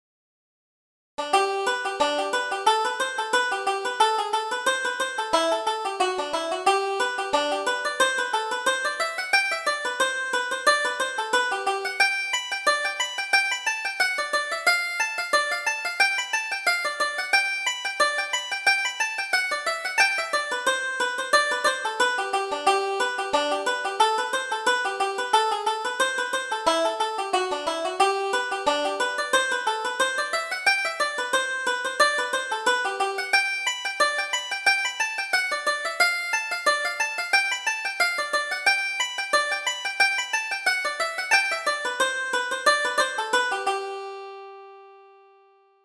Reel: Sheehan's Reel